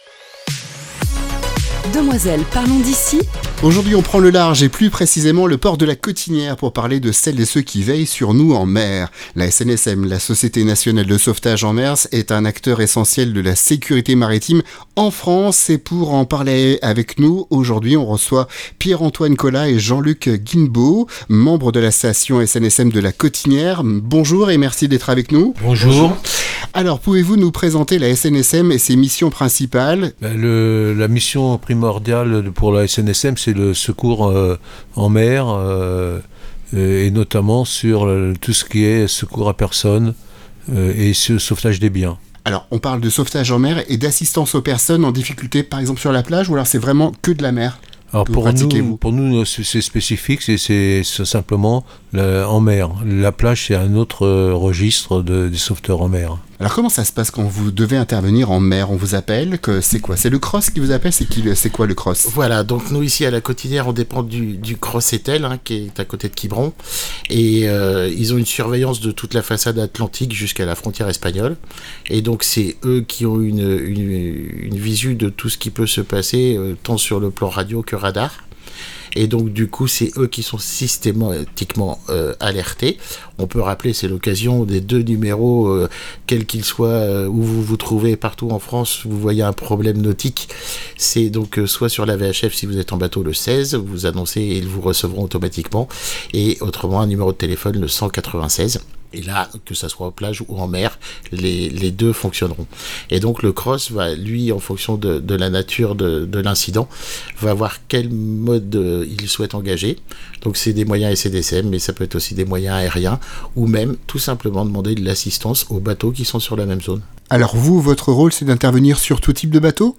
Les héros de la mer – Rencontre